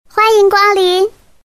萝莉音欢迎光临音效_人物音效音效配乐_免费素材下载_提案神器
萝莉音欢迎光临音效免费音频素材下载